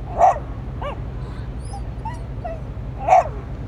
dog-dataset
puppy_0037.wav